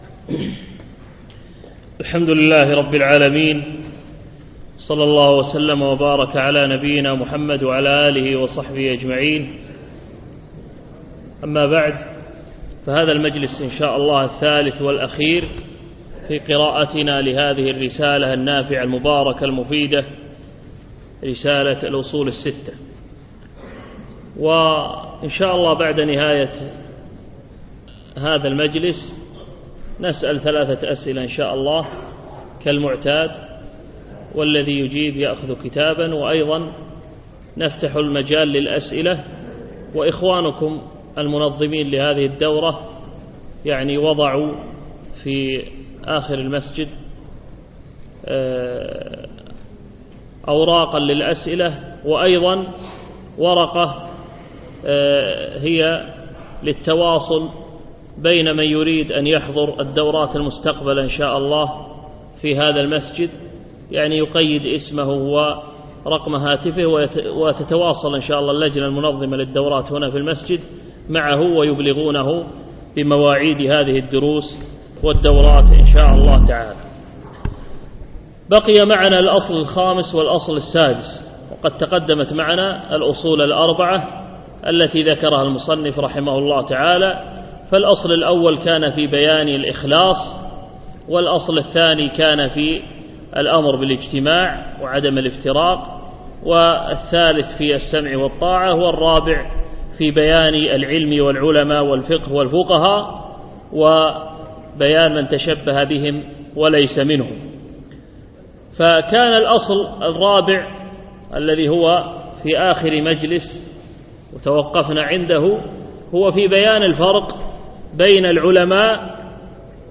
يوم السبت 20 رجب 1436 الموافق 9 5 2015 بمسجد فهد سند العجمي خيطان